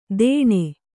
♪ dēṇe